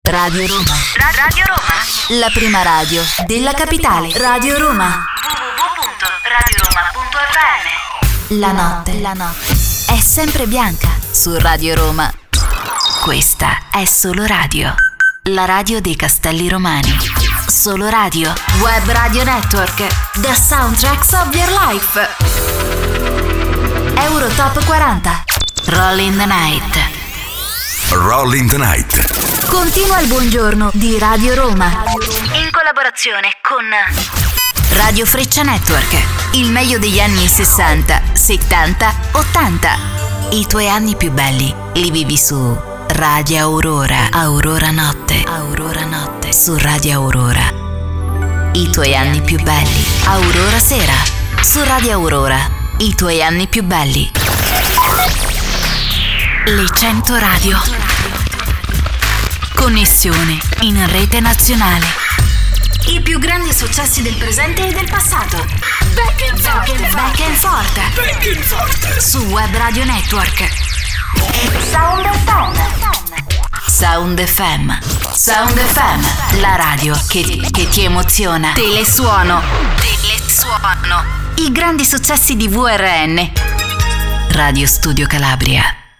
I have my own recording studio, equipped with the most advanced devices on the market.
Sprechprobe: Industrie (Muttersprache):
I record and mix everyday full day into my real professional home studio.